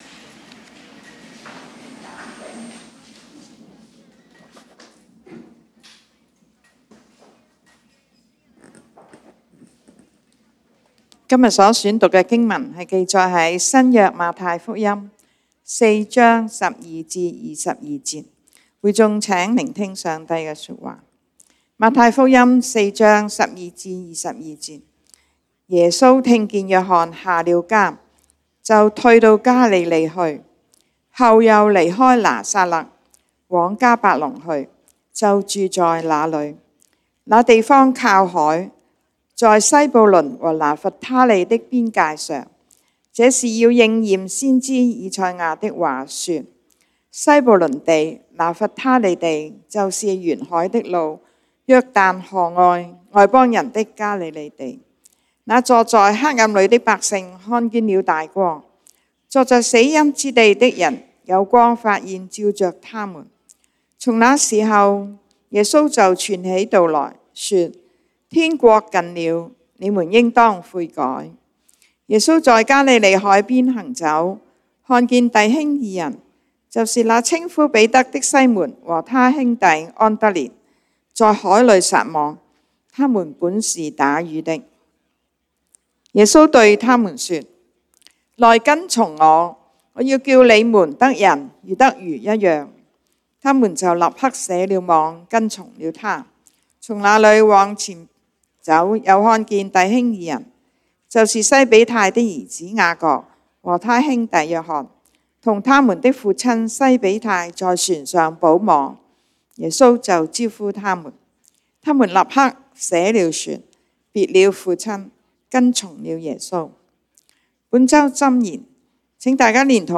1/26/2020 粵語堂主日崇拜 講道經文：《馬太福音》Matthew 4:12-22 本週箴言：《馬太福音》Matthew 3:8 「你們要結出果子來，與悔改的心相稱。」